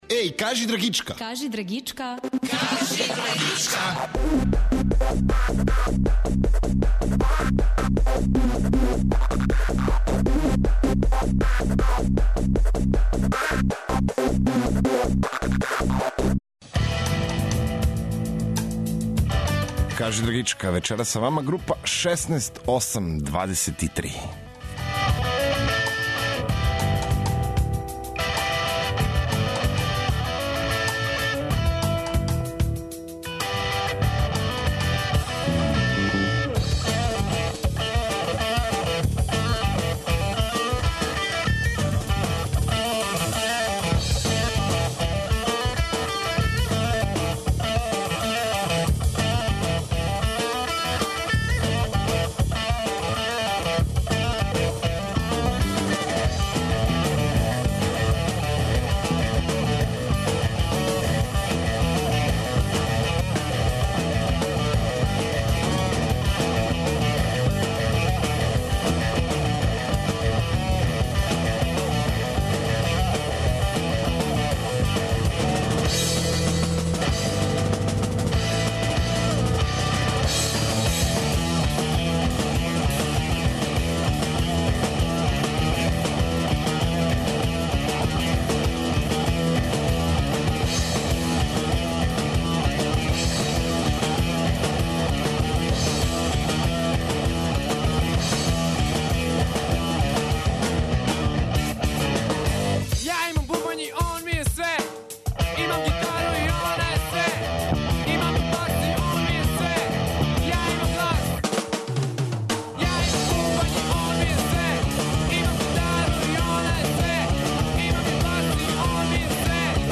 Слушамо премијерно песме са њиховог новог ЕП-а, као и песме по њиховом избору.